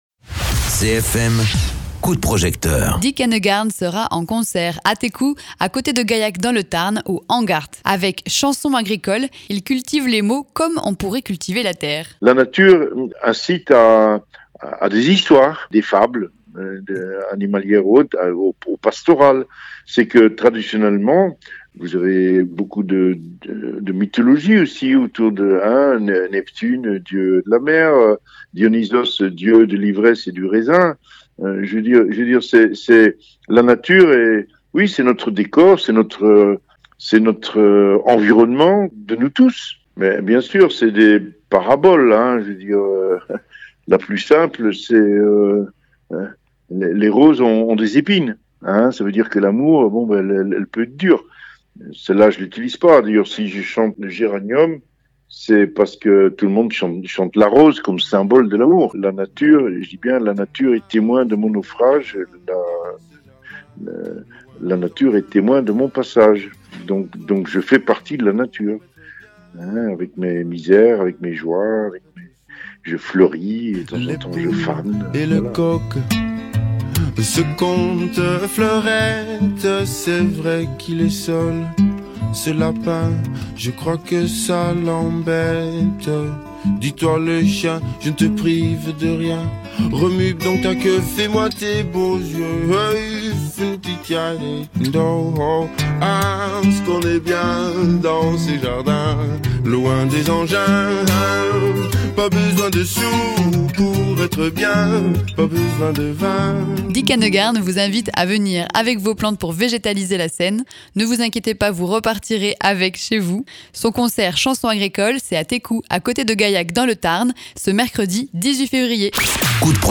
Interviews
Invité(s) : Dick Annegarn, auteur, compositeur, interprète